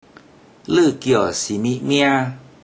Click each Romanised Teochew word or phrase to listen to how the Teochew word or phrase is pronounced.
Ler4 kio54 si20mi54 mia1?